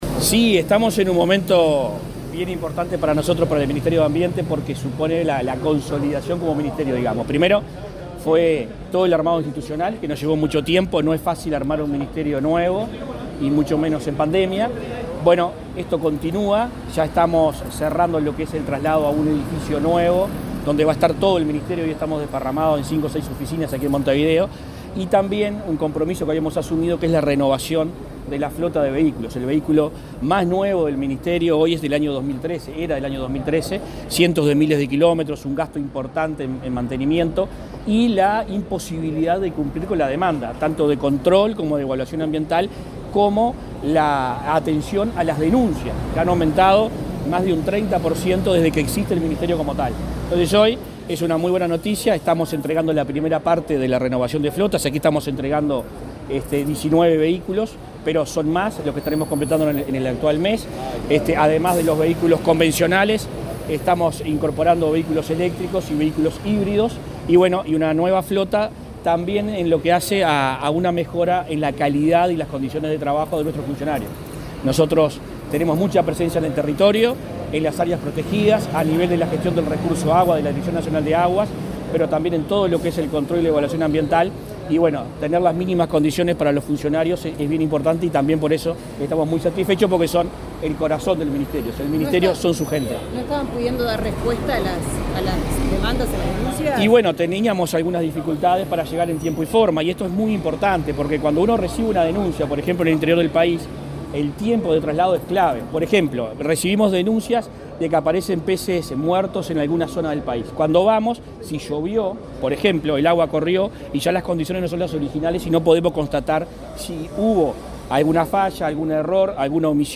Declaraciones a la prensa del ministro de Ambiente, Adrián Peña
El ministro de Ambiente, Adrián Peña, dialogó con la prensa sobre la incorporación de vehículos a la flota de la cartera estatal, entre otros temas.